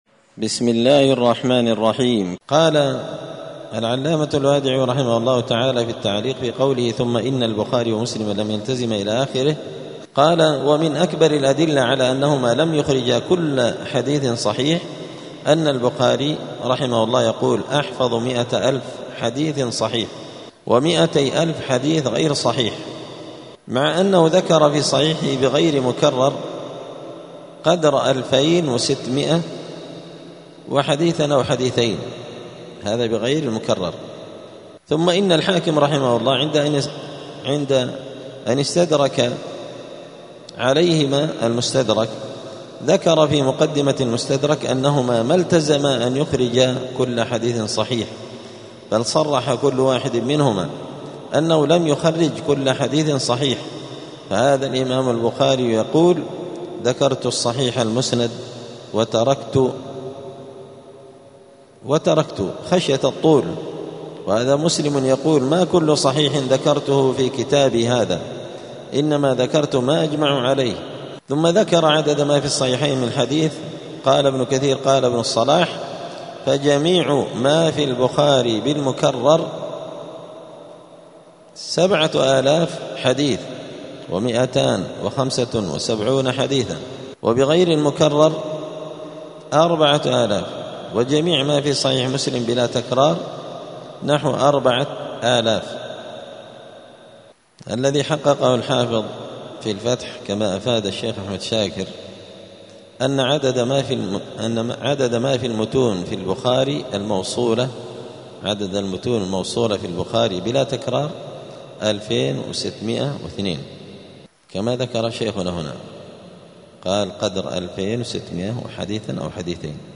السير الحثيث شرح اختصار علوم الحديث – الدرس السادس (6) : عدد ما في الصحيحين من الحديث.
دار الحديث السلفية بمسجد الفرقان قشن المهرة اليمن